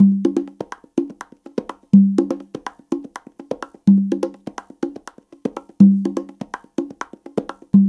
CONGA BEAT40.wav